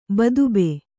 ♪ badube